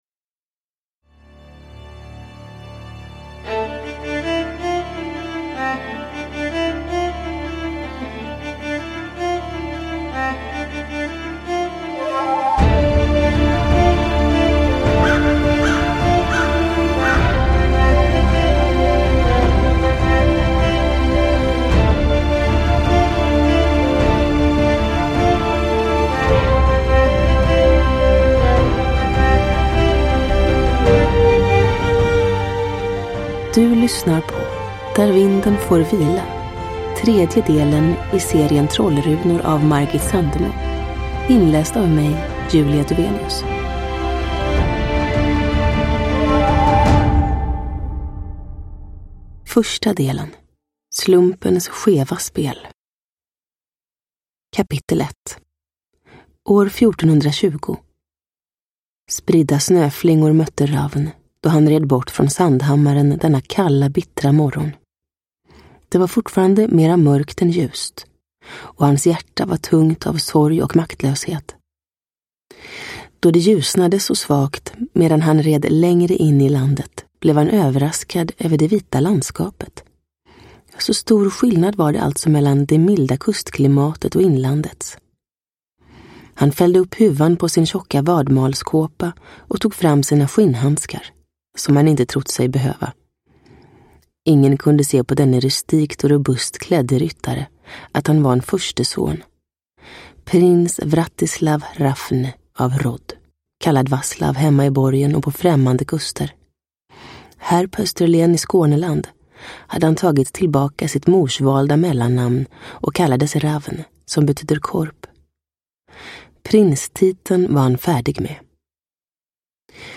Där vinden får vila – Ljudbok – Laddas ner
Uppläsare: Julia Dufvenius